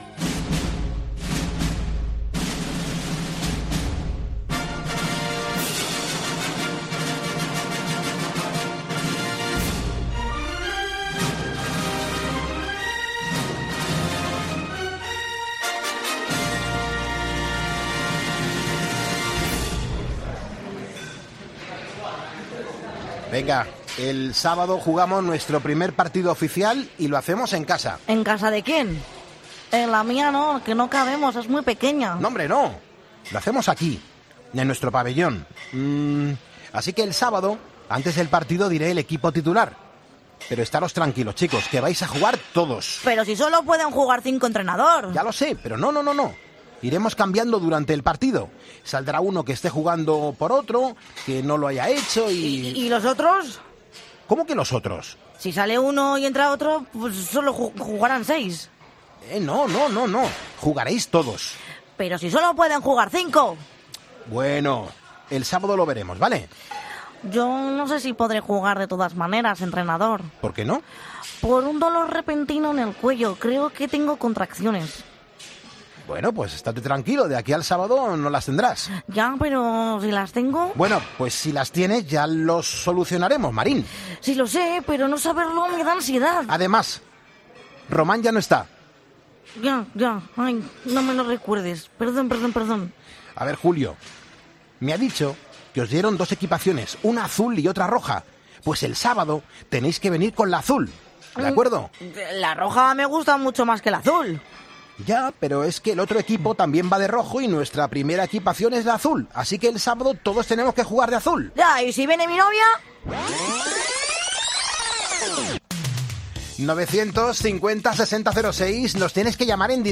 Como cada viernes, en Poniendo Las Calles, nos hacemos pasar por actores de doblaje y sale lo que sale... nosotros lo intentamos.